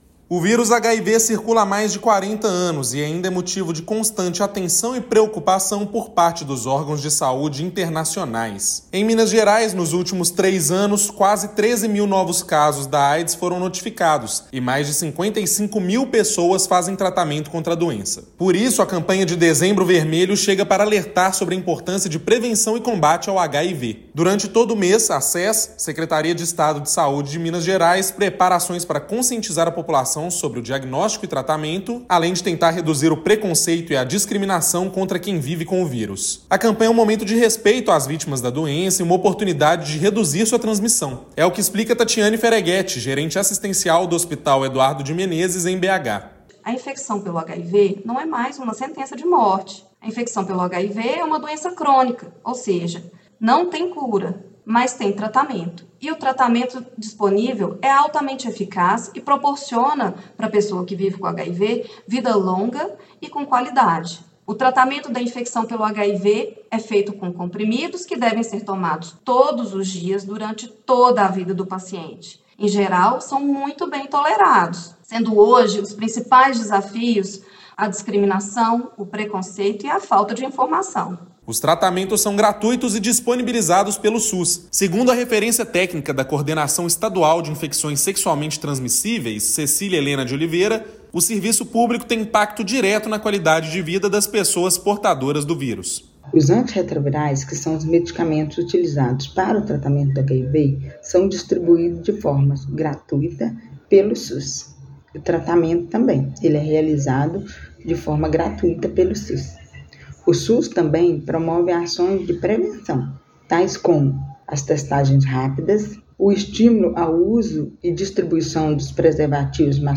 [RÁDIO] Dezembro Vermelho chama a atenção para prevenção e combate ao HIV/Aids
Em Minas Gerais, 12.980 casos foram notificados nos últimos 3 anos, e 55.122 pessoas estão em tratamento. Ouça matéria de rádio.